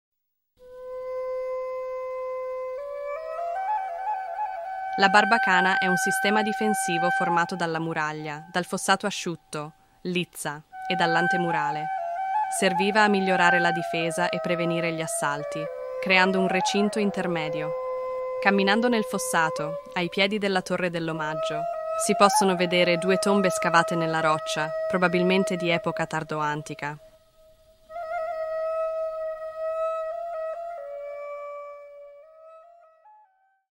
Ruta audioguiada
audioguia-italiano-qr8.mp3